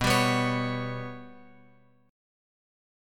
Bsus2#5 chord {x x 9 6 8 7} chord